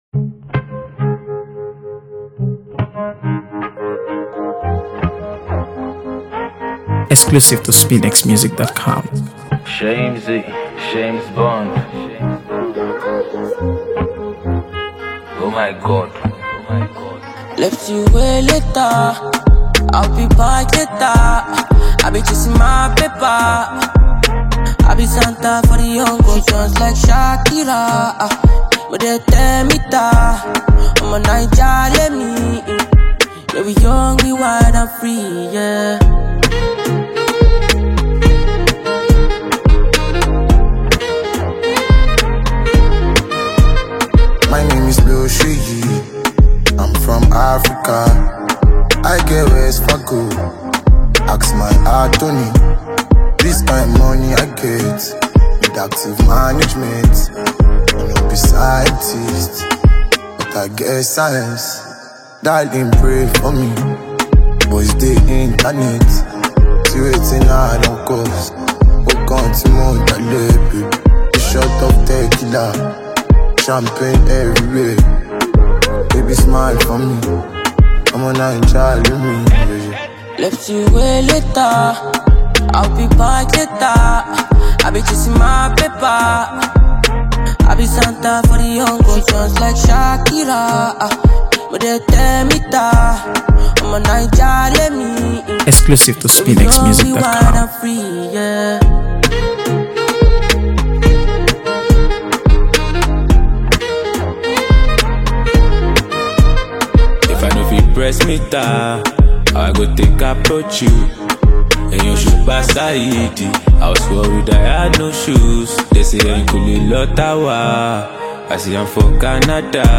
AfroBeats | AfroBeats songs